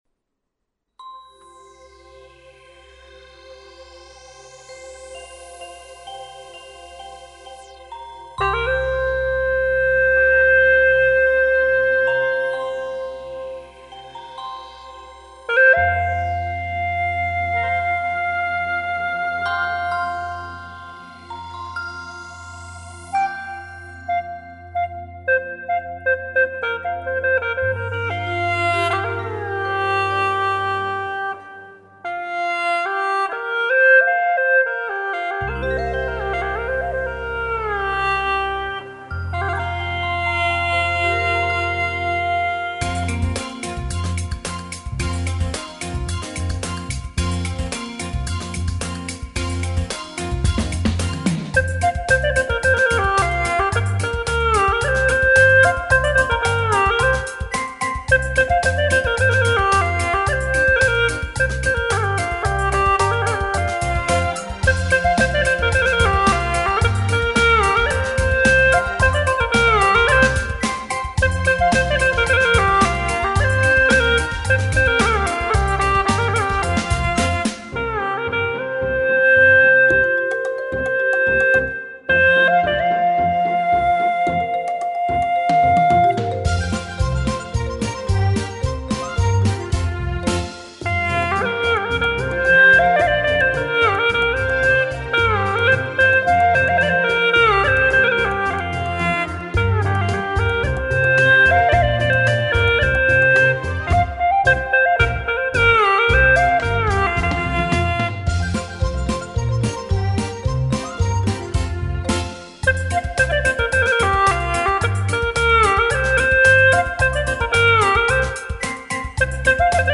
调式 : 降B 曲类 : 独奏